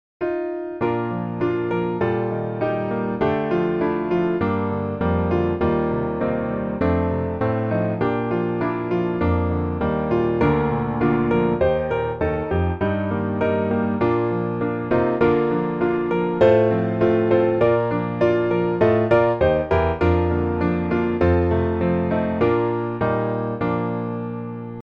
降B大调